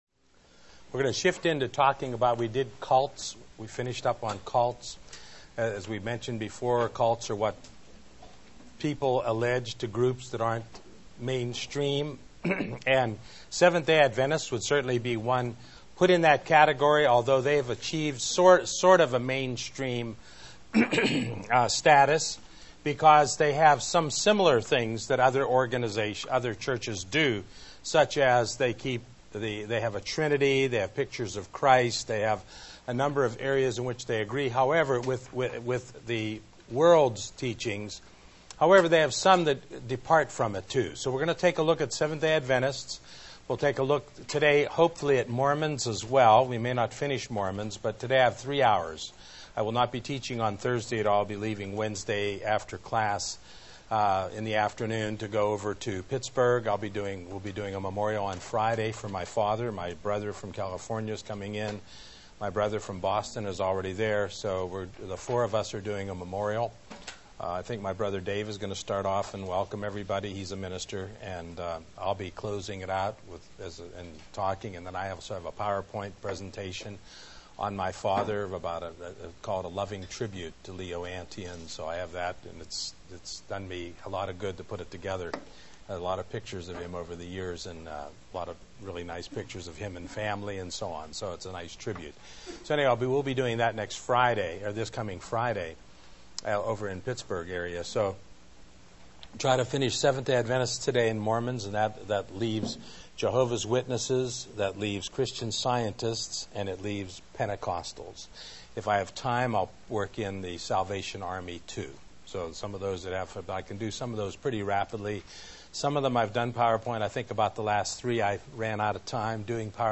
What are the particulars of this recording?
During the 2006 class year we were able to record the Comparative Religion class